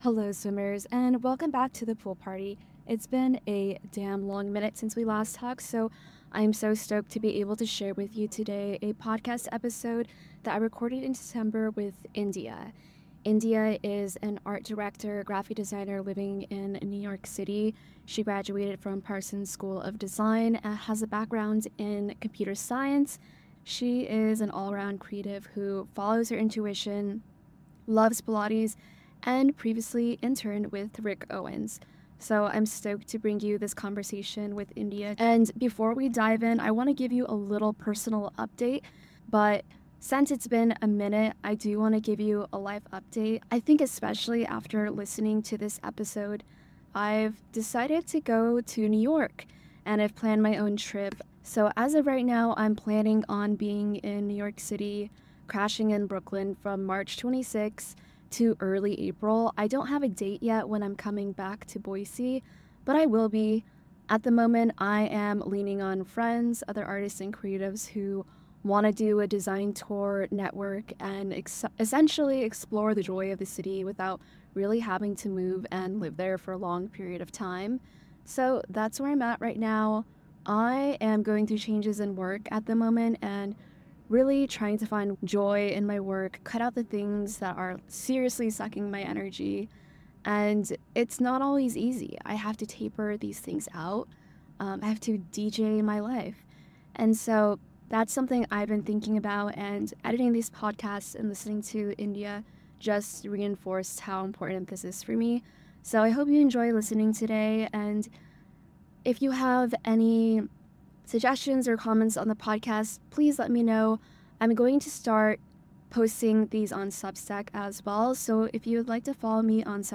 a conversation recorded in December